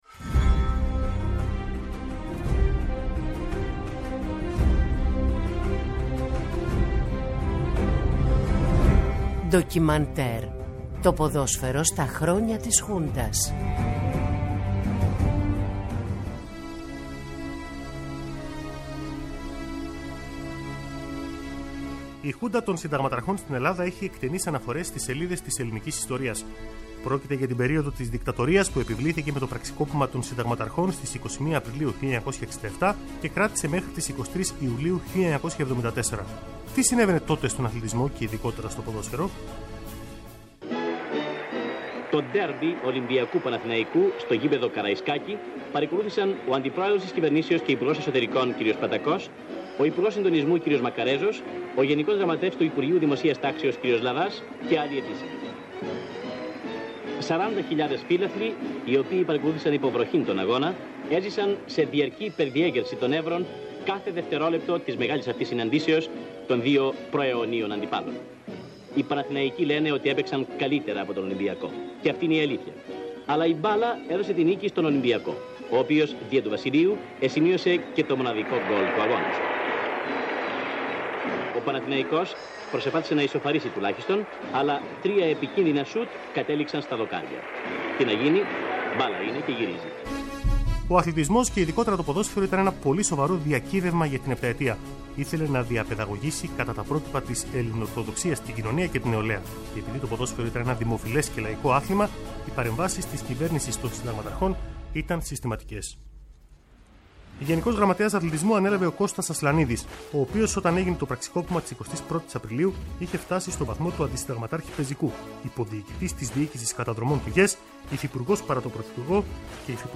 Σπάνια ηχητικά ντοκουμέντα και συνεντεύξεις ανθρώπων που το έζησαν μέσα από τις ομάδες τους.